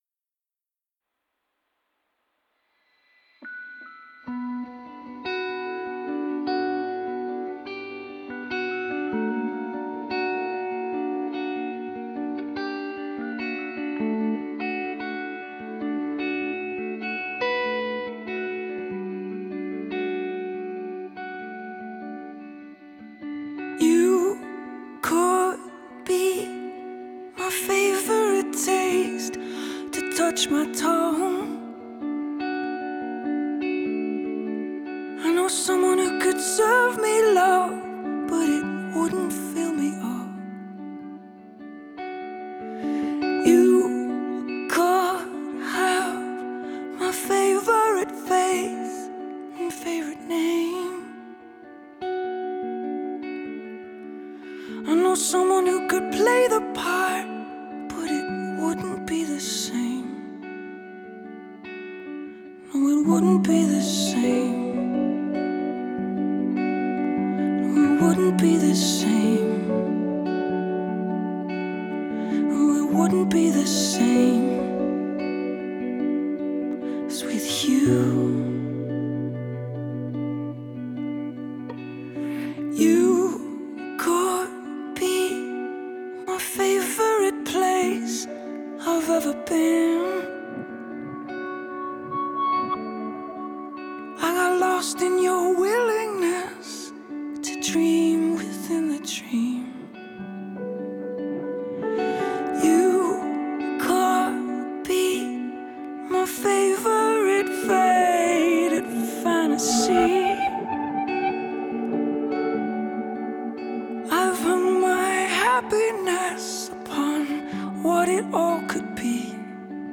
Irish alt-folk songwriter